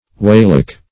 Search Result for " weighlock" : The Collaborative International Dictionary of English v.0.48: Weighlock \Weigh"lock`\, n. A lock, as on a canal, in which boats are weighed and their tonnage is settled.